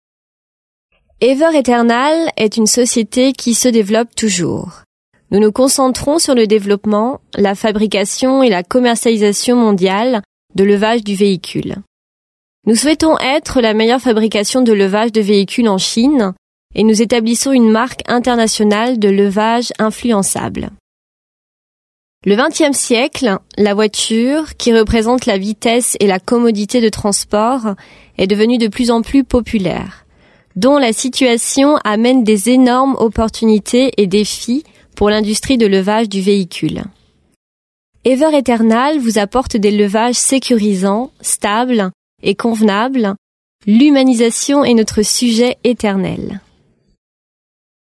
外语配音：俄语语配音 日语配音 韩语配音 法语配音 德语配音 西班牙语配音和葡萄牙语配音员及其他小语种配音演员